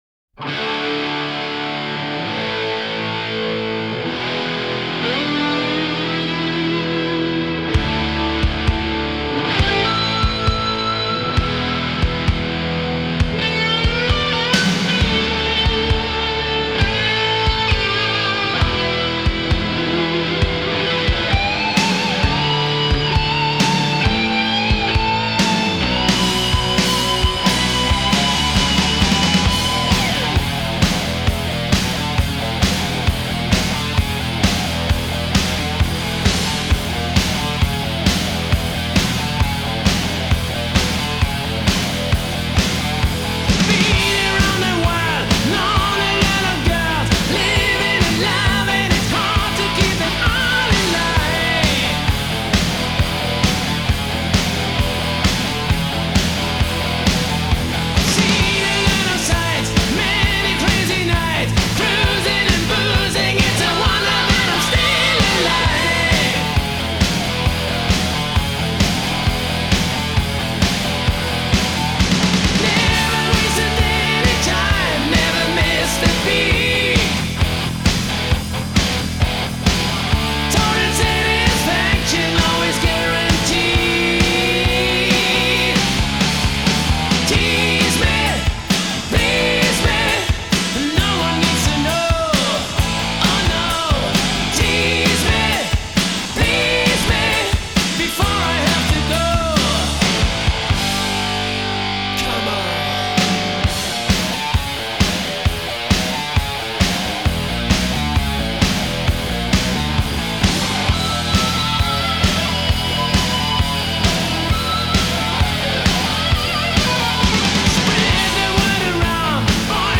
اجرای زنده 1988-1994
Hard Rock, Heavy Metal